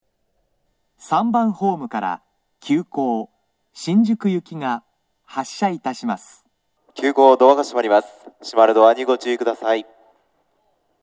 2010年11月より接近放送の冒頭に、『いきものがかり』の楽曲、「YELL」が接近メロディーとして流れています。
（男性）
接近放送
急行　新宿行（10両編成）の接近放送です。